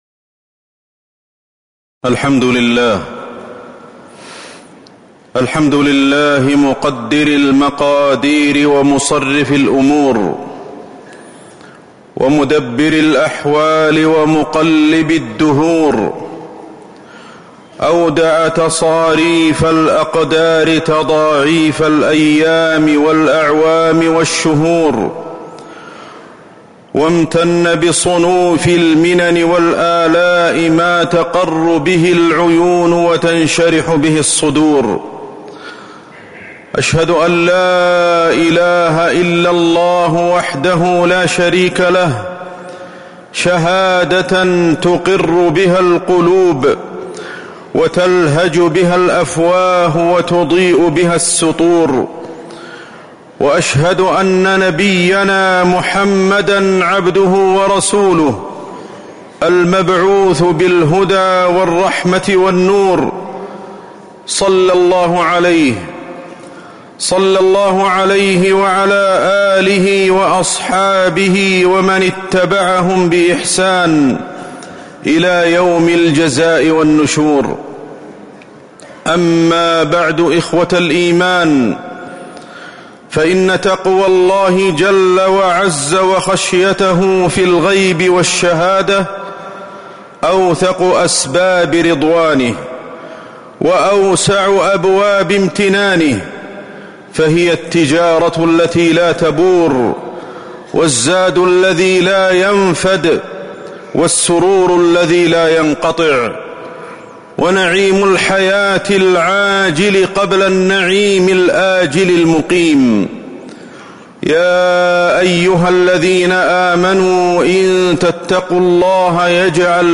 تاريخ النشر ٢ محرم ١٤٤٧ هـ المكان: المسجد النبوي الشيخ: فضيلة الشيخ أحمد بن علي الحذيفي فضيلة الشيخ أحمد بن علي الحذيفي استقبال العام الجديد